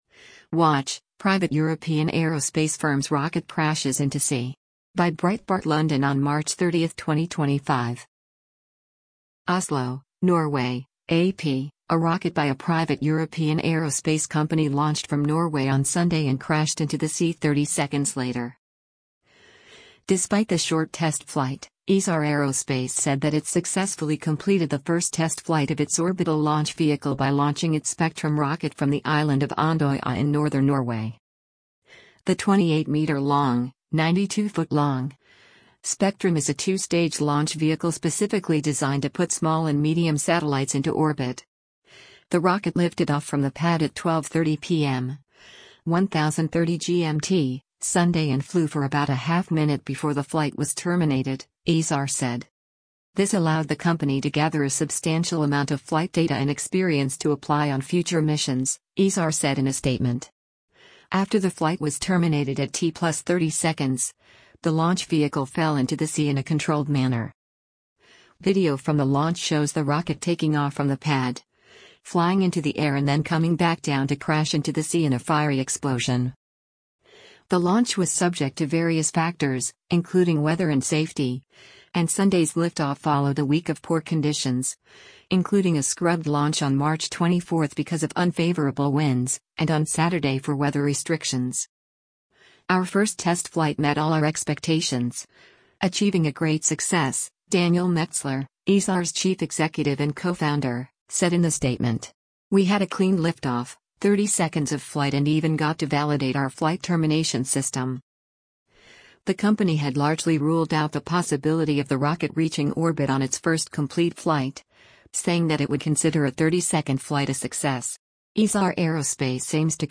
Video from the launch shows the rocket taking off from the pad, flying into the air and then coming back down to crash into the sea in a fiery explosion.